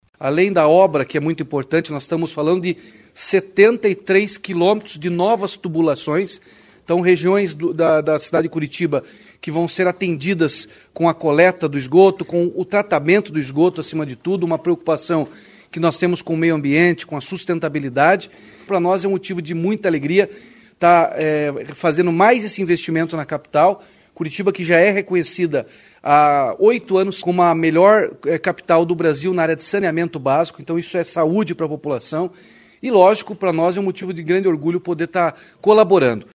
Segundo o governador Ratinho Junior, as obras fazem parte da meta de universalizar o saneamento em Curitiba, com impacto direto em saúde pública, sustentabilidade e preservação ambiental.